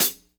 Index of /90_sSampleCDs/Best Service Dance Mega Drums/HIHAT HIP 1B